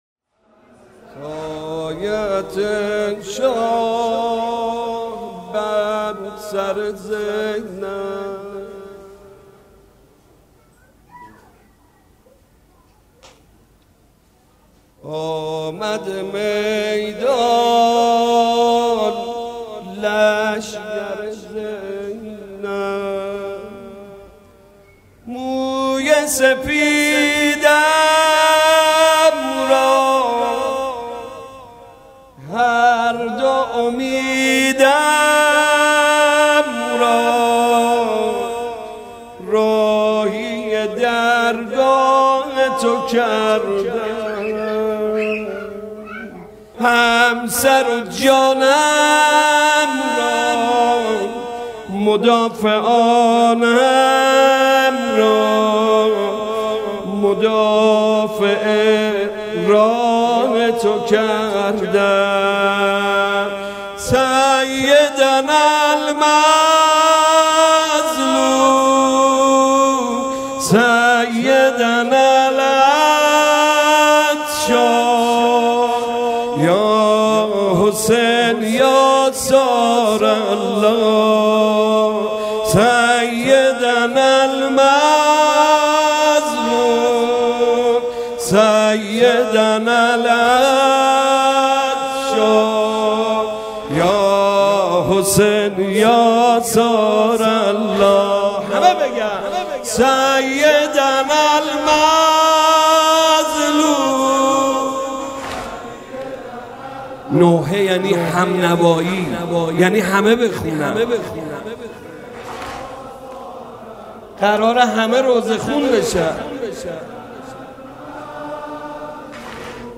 محرم99 - شب چهارم - نوحه - سایهات ای شاه بر سر زینب